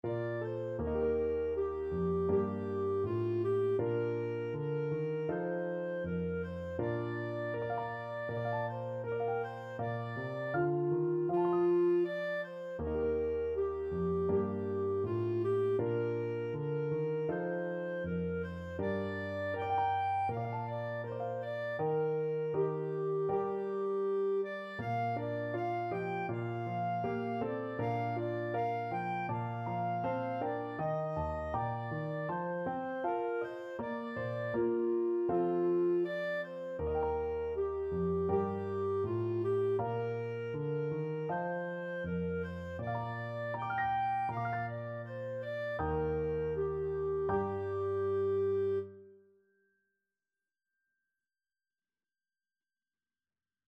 4/4 (View more 4/4 Music)
Andante Espressivo = c. 80